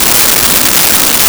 Static Loop 02
Static Loop 02.wav